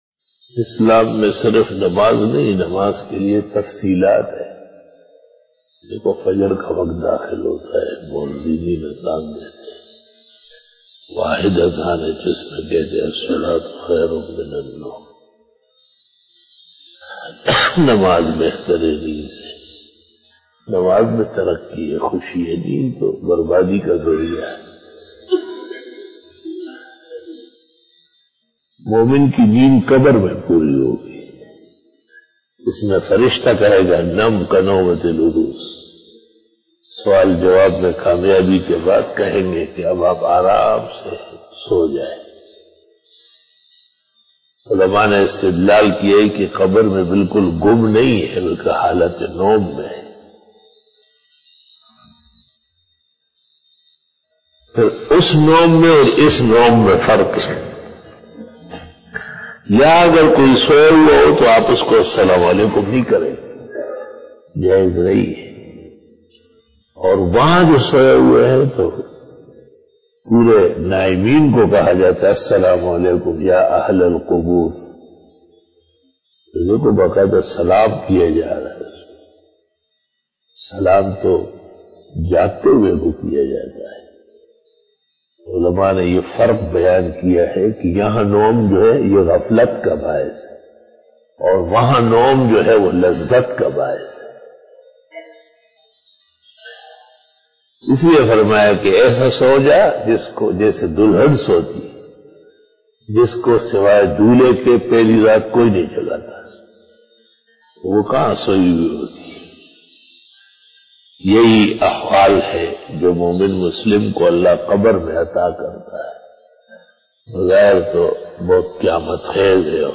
After Fajar Byan